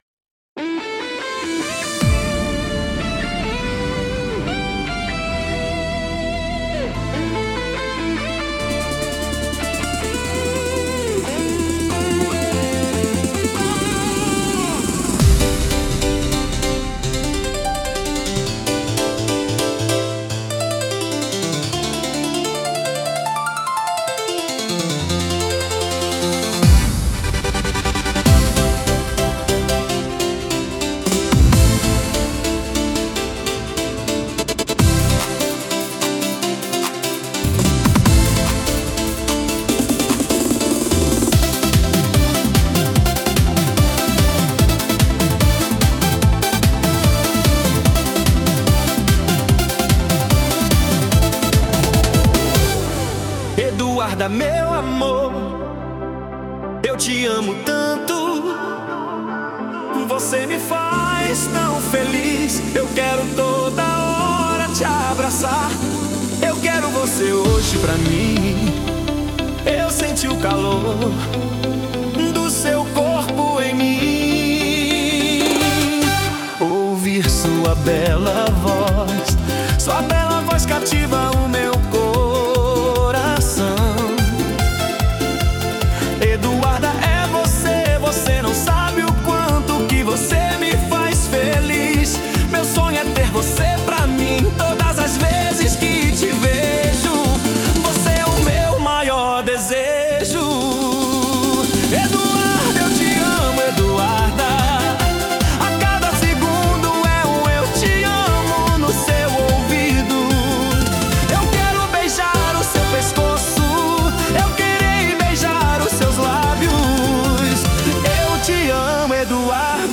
Versão Brega 1